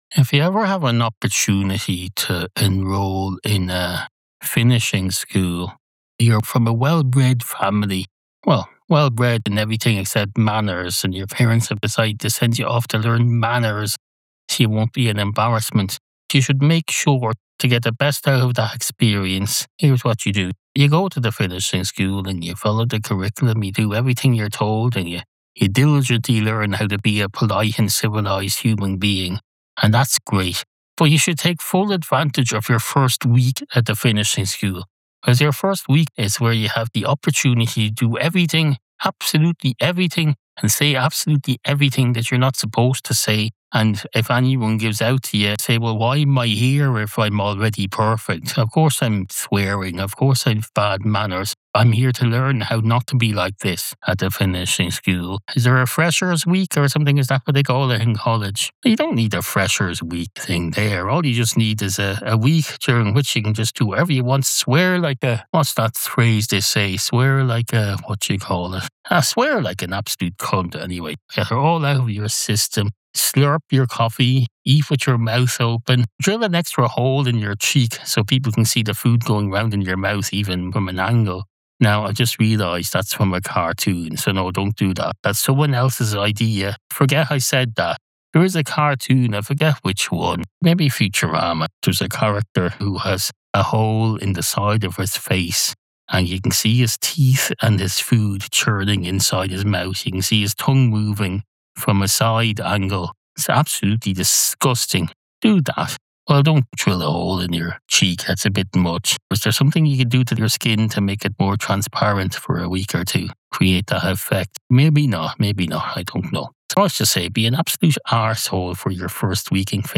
Be a guest on this podcast Language: en-ie Genres: Comedy , Improv , Stand-Up Contact email: Get it Feed URL: Get it iTunes ID: Get it Get all podcast data Listen Now...